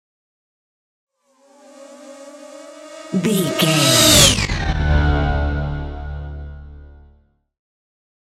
Sci fi ship vehicle pass by
Sound Effects
futuristic
pass by